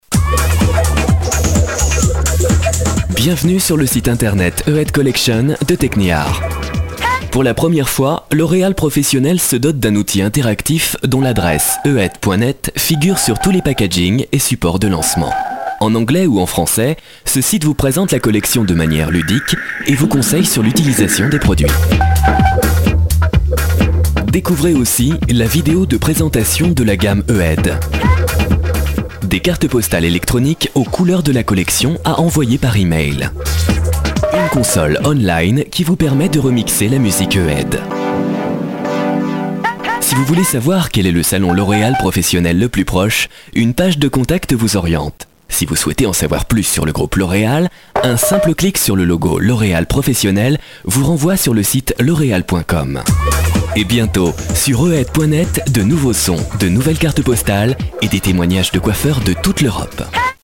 Voix off motion design
Une voix claire, maîtrisée, au service du mouvement et de l’idée.
5. L'OREAL corporate